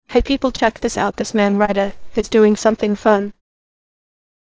voice-cloning-AI - Voice cloning AI (deepfake for voice). Using cloned voice from only 5-10 seconds of targeted voice.